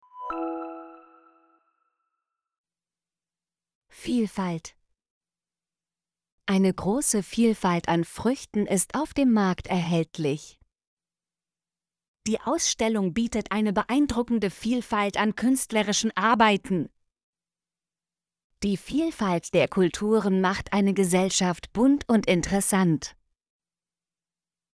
vielfalt - Example Sentences & Pronunciation, German Frequency List